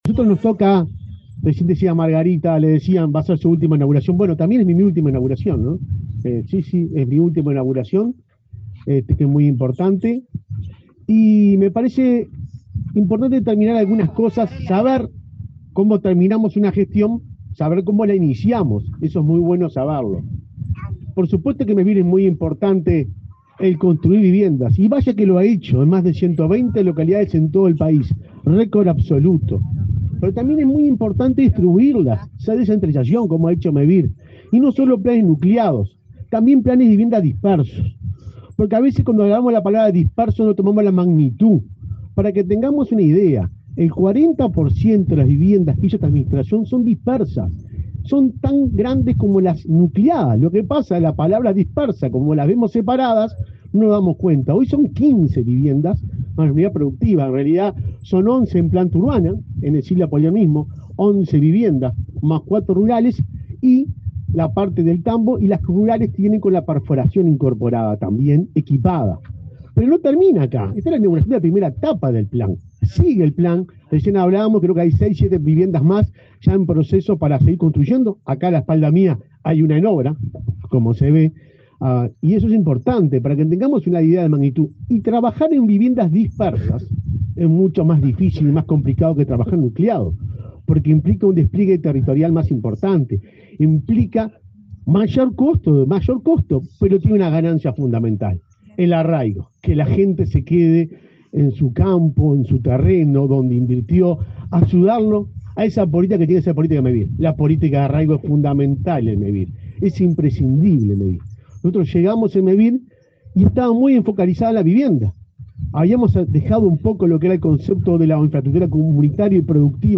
Palabra de autoridades en inauguración de Mevir en San José